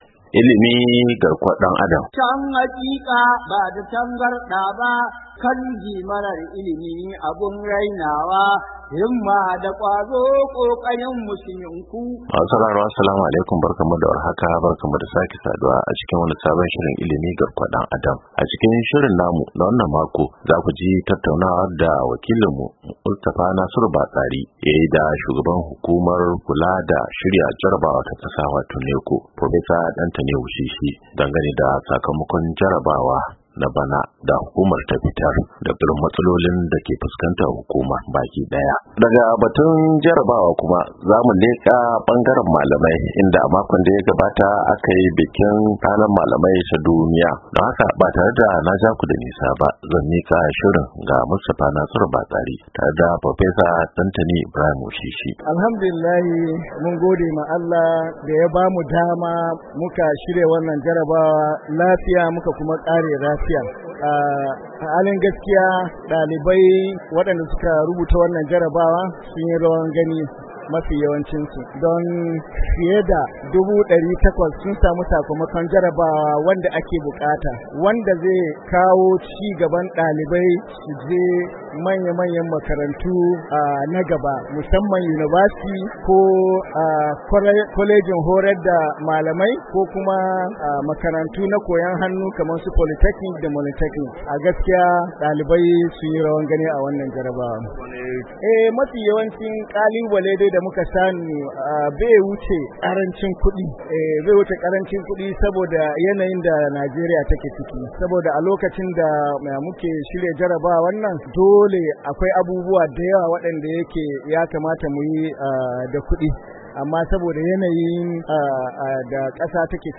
ILIMI GARKUWAR DAN ADAM: Hira Ta Musamman Da Shugaban NECO Kan Sakamakon Jarabawar Bana Oktoba 14, 2024